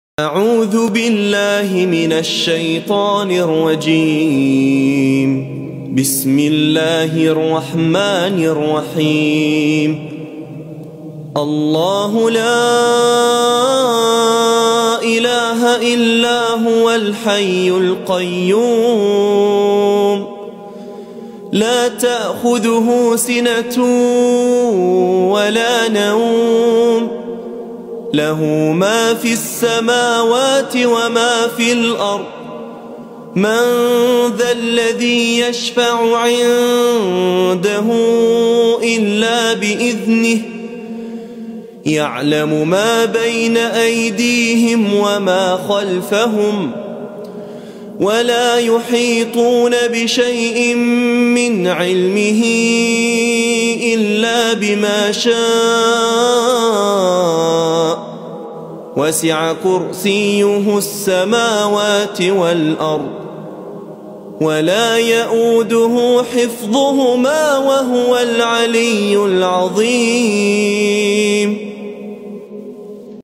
Ayatul Kursi Full - Beautiful Recitation
Ayatul Kursi Full - Beautiful Recitation.mp3